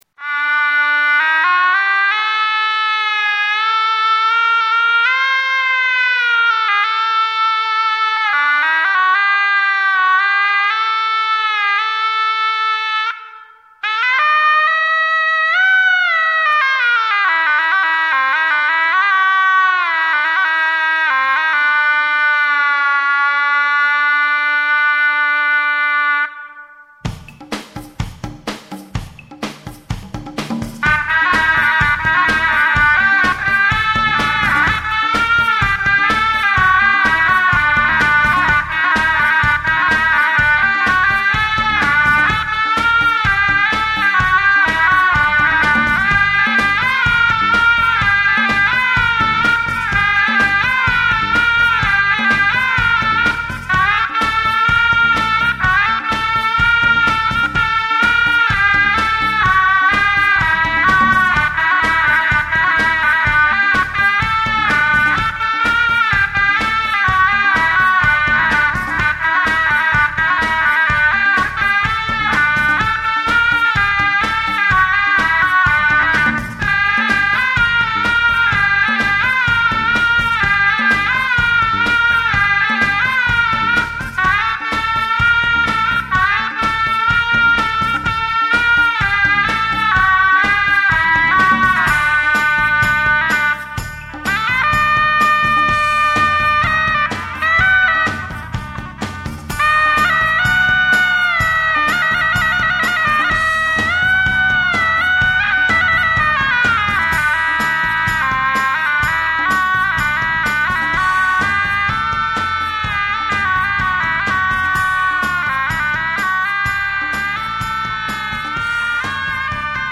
Egyptian music with a Western thump...
mixing modern and ancient instruments.